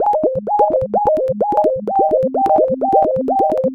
Bubble Bubble Ab 128.wav